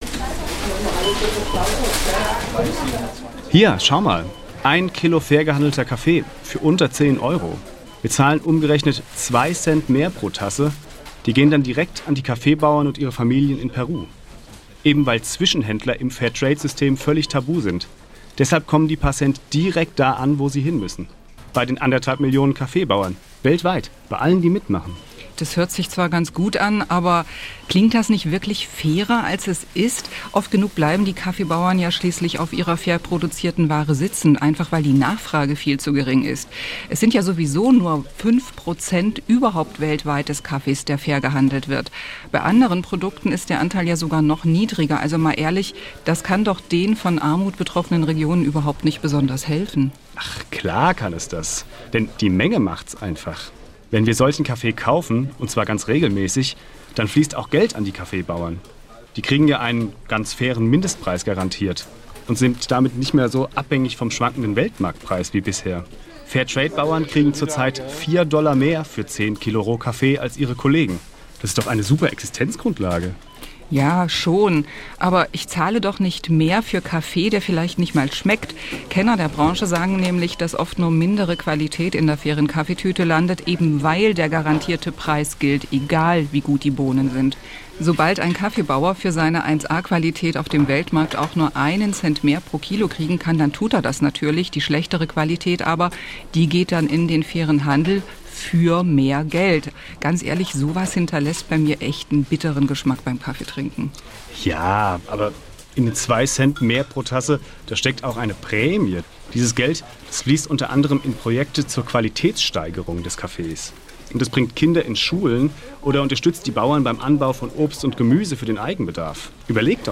im Zwiegespräch diskutieren, wie gerecht "Fairer Handel" ist?